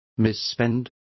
Complete with pronunciation of the translation of misspent.